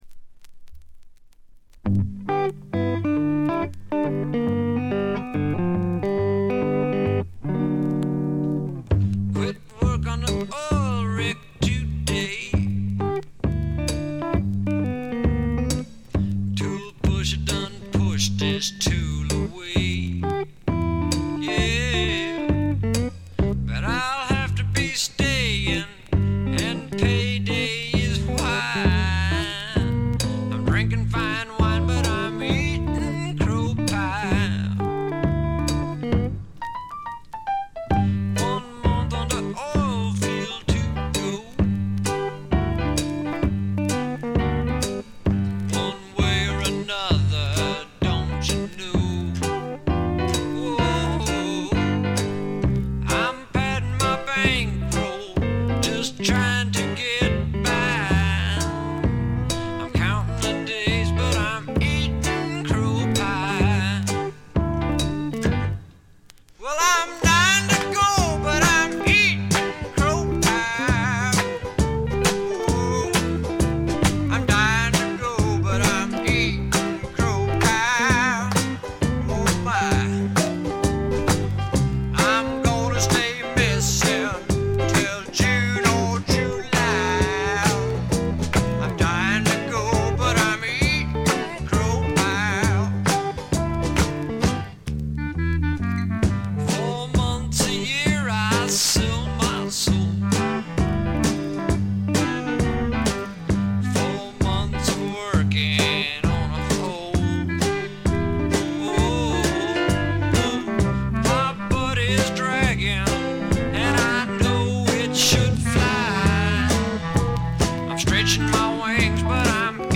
B1フェードアウトからB2始めチリプチ。
ザ・バンド的な緻密さとスワンプ・ロックの豪快さを併せ持つ理想的なサウンドですね。
試聴曲は現品からの取り込み音源です。
※B1-B2連続です。曲間のノイズをご確認ください。